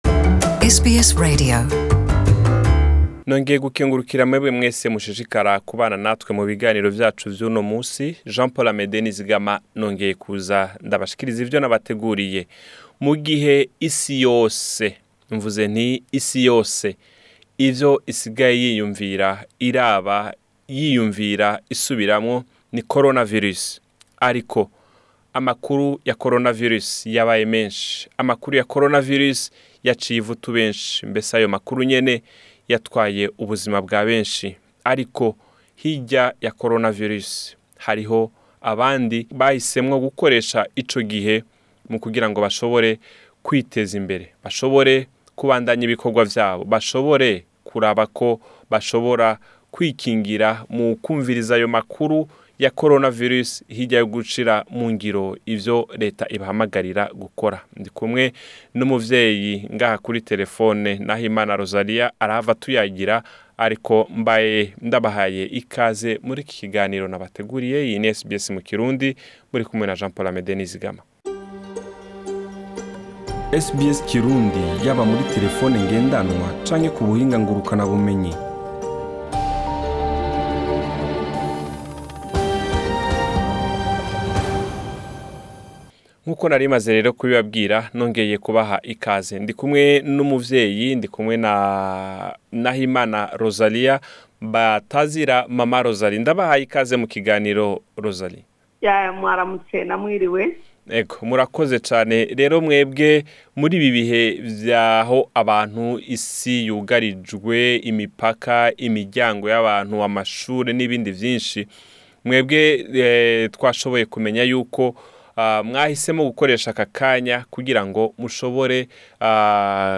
kiganiro kidasanzwe